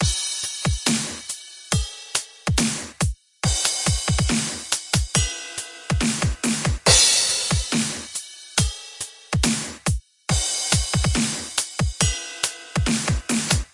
沉重的Dubstep节拍 - 声音 - 淘声网 - 免费音效素材资源|视频游戏配乐下载
一个艰难的dubstep击败踢，圈套，骑，hihat和cy钹。
在FL studio 11中创建并录制。